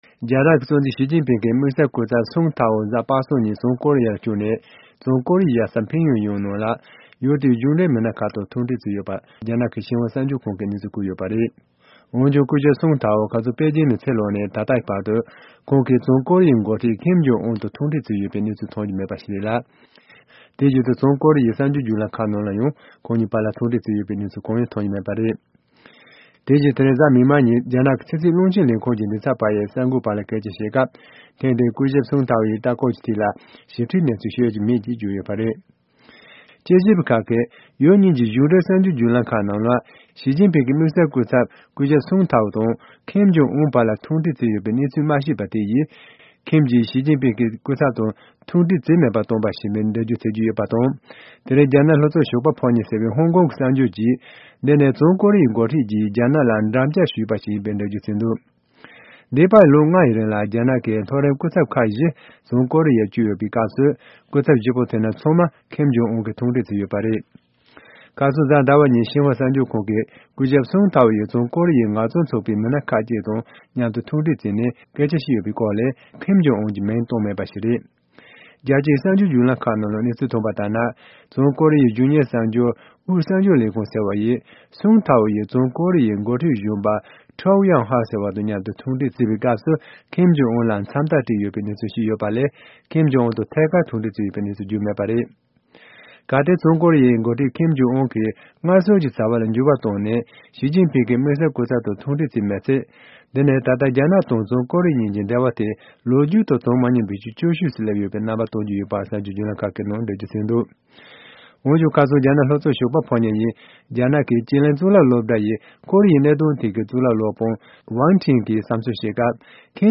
ཕྱོགས་བསྒྲིགས་དང་སྙན་སྒྲོན་ཞུས་གནང་གི་རེད།